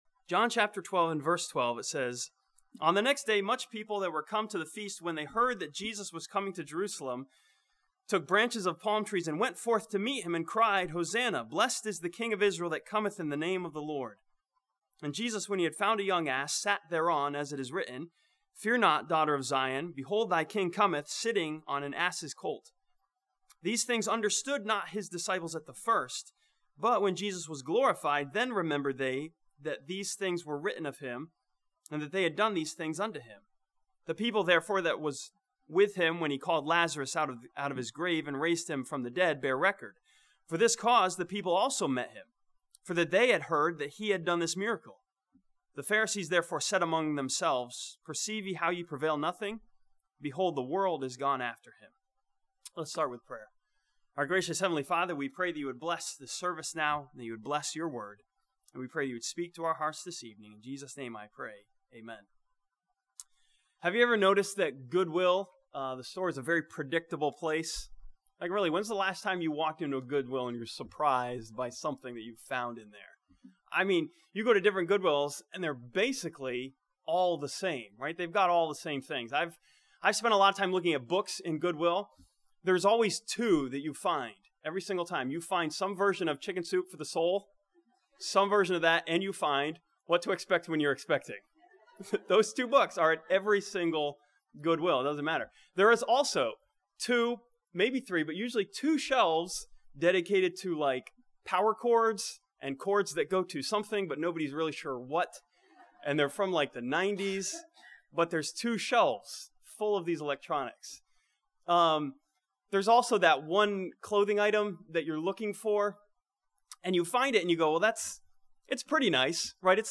This sermon from John chapter 12 teaches that Jesus Christ is a predictable King who always reigns in peace and power.